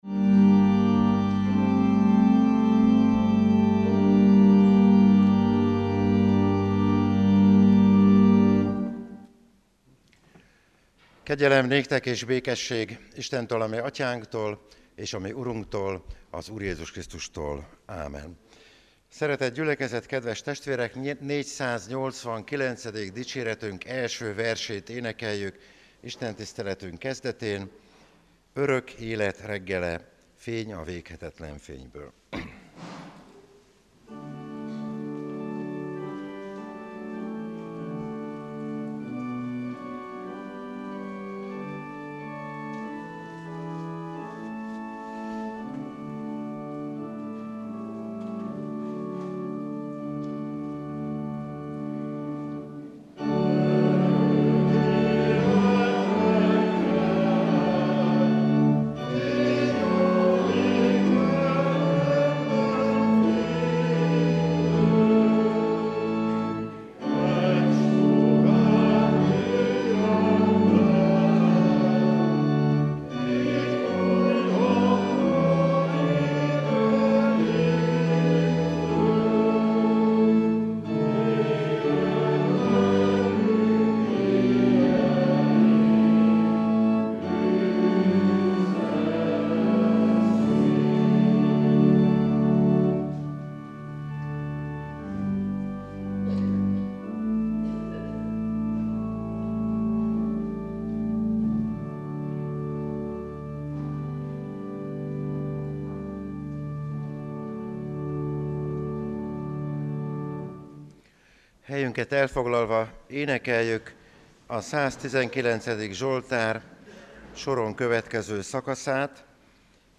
Lekció: Pál levele a kolossébelieknek 1. rész 3-14. versek